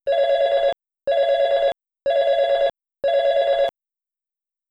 El aviso de alarma/despertador es 4 rings cortos y un silencio,
ring alarma.wav